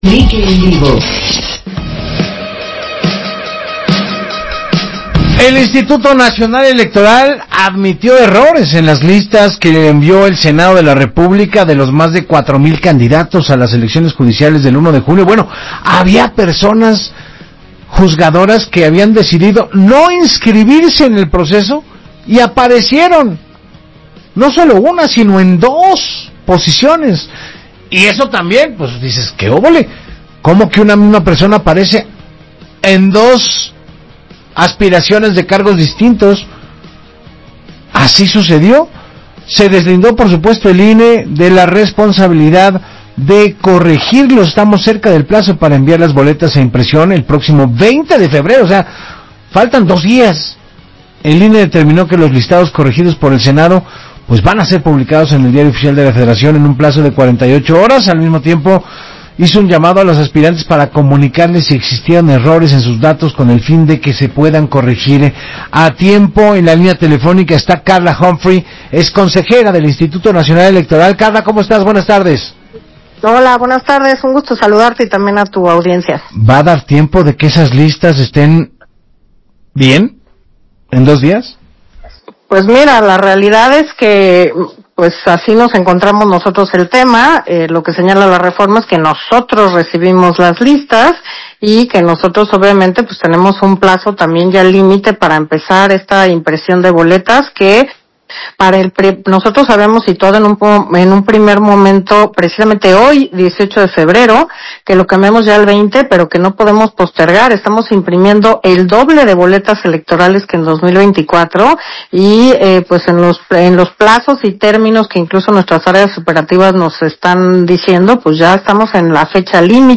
Audio de la entrevista de la Consejera Electoral Carla Humphrey con Enrique Hernández Alcázar para W Radio